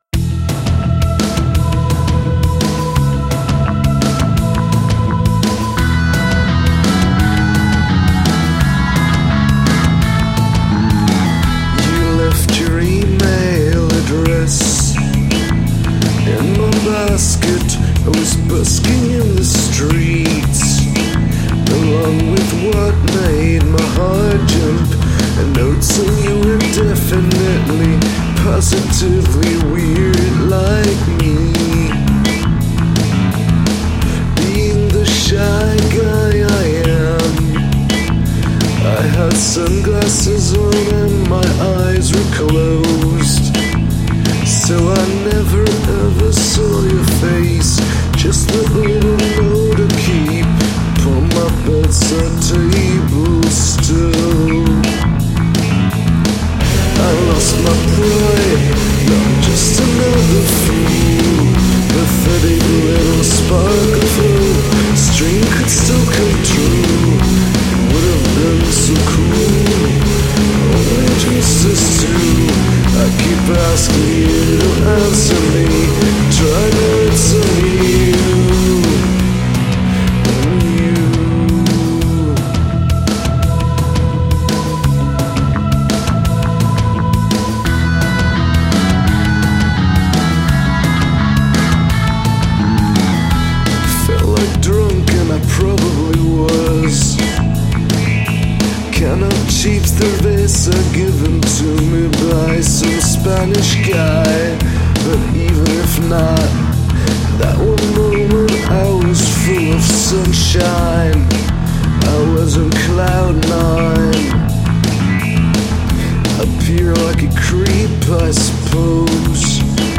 I like your sick voice a lot.
Cool noises along the way.